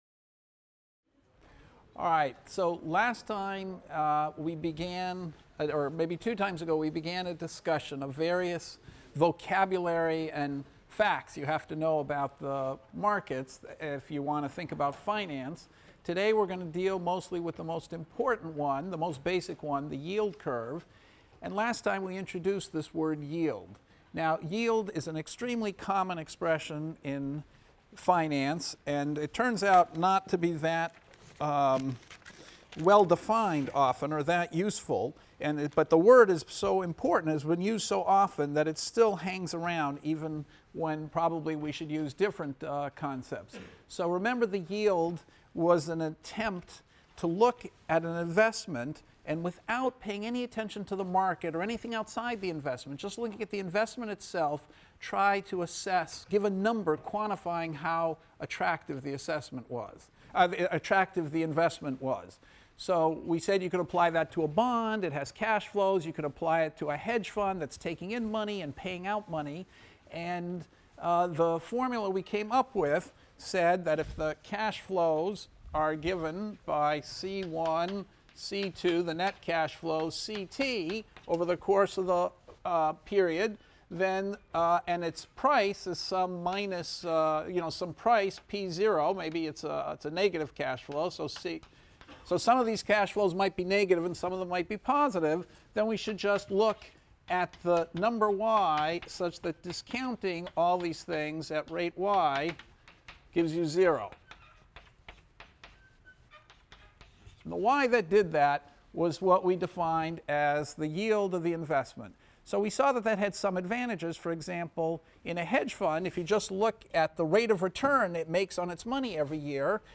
ECON 251 - Lecture 9 - Yield Curve Arbitrage | Open Yale Courses